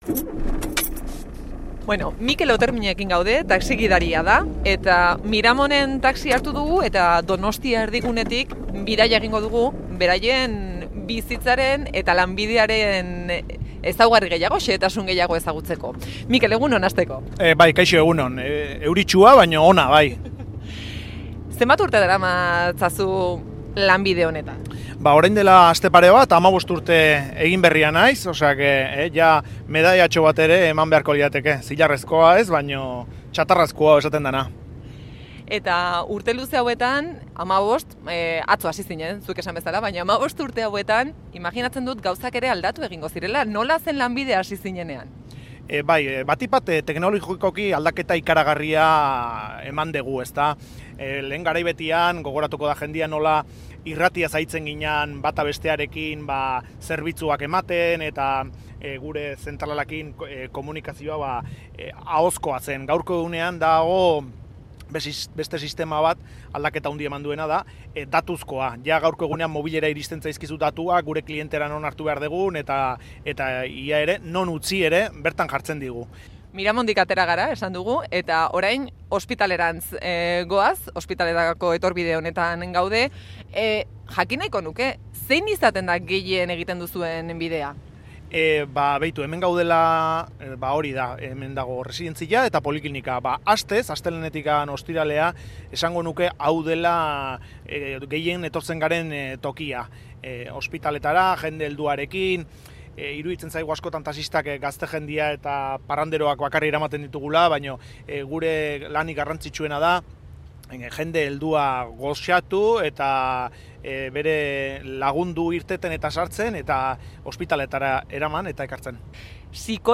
Donostia erdigunetik bidaia egin dugu